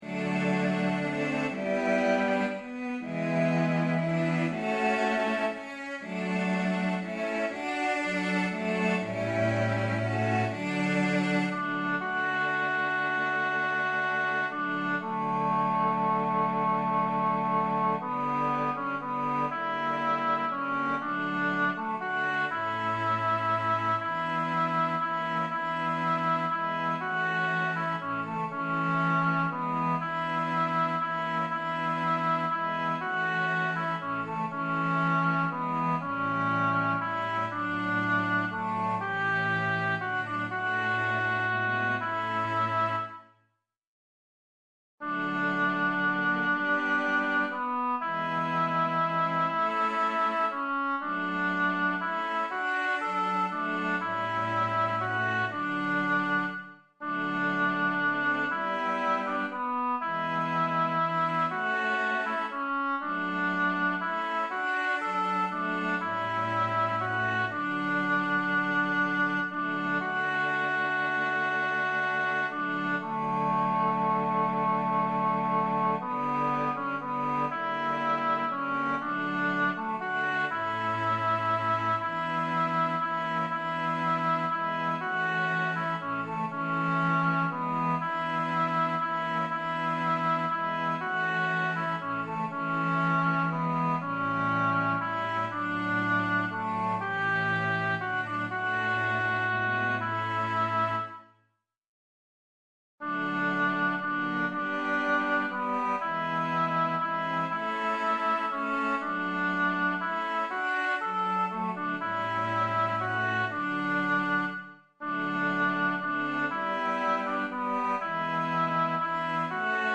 Wie schön bist du (Die Nacht) 3 Strophen Tenor 1 als Mp3
wie-schoen-bist-du-die-nacht-3-strophen-einstudierung-tenor-1.mp3